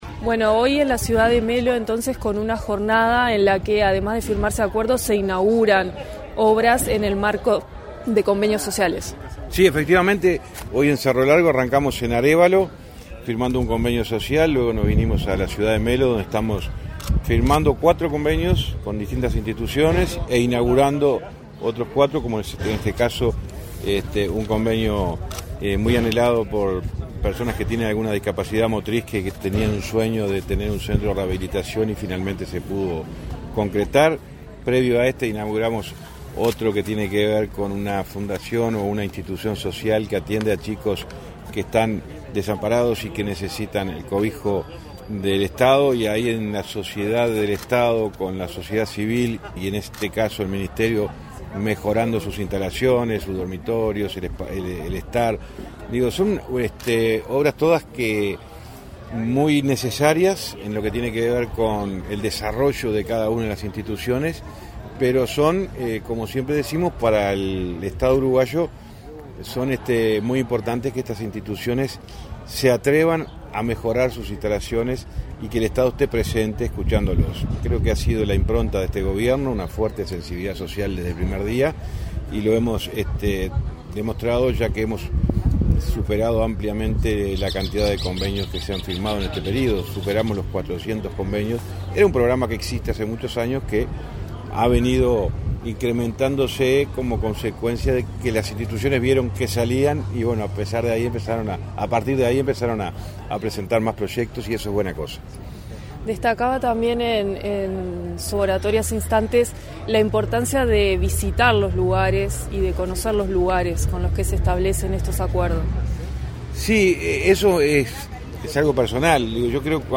Entrevista al ministro de Transporte y Obras Públicas, José Luis Falero
Entrevista al ministro de Transporte y Obras Públicas, José Luis Falero 04/12/2024 Compartir Facebook X Copiar enlace WhatsApp LinkedIn Tras participar en la inauguración del centro de atención a la discapacidad UDI 3 de Diciembre, en Melo, Cerro Largo, este 4 de diciembre, el ministro de Transporte y Obras Públicas, José Luis Falero, realizó declaraciones a Comunicación Presidencial.